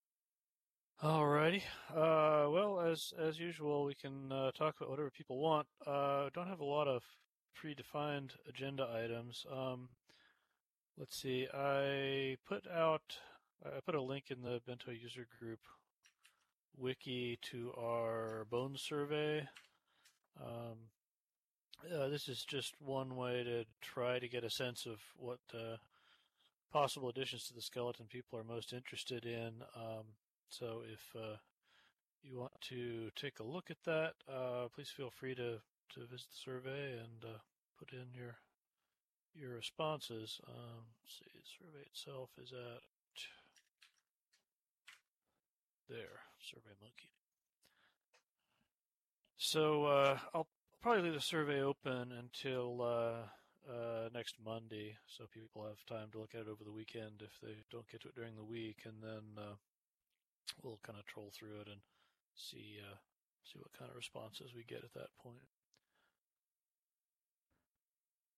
The following notes and audio were taken from the weekly Bento User Group meeting, held on Aditi every Thursday at 13:00 SLT.